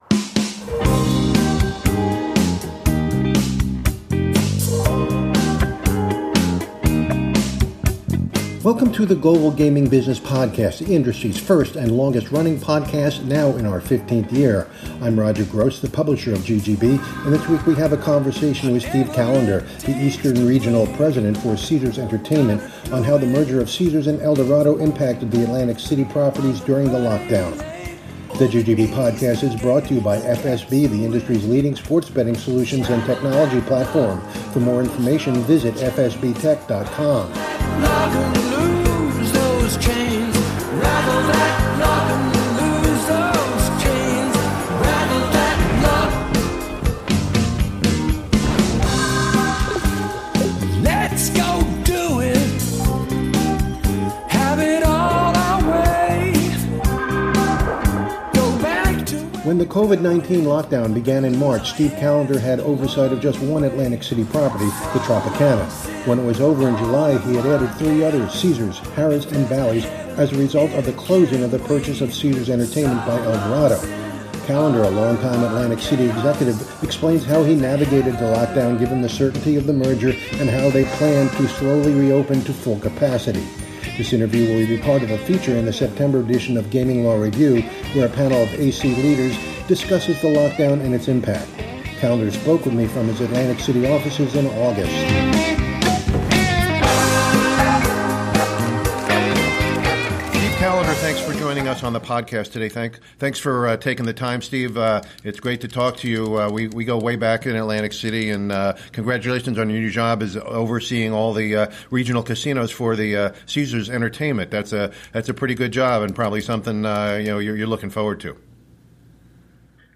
This interview will be part of feature in the September edition of Gaming Law Review, where a panel of AC leaders discusses the lockdown and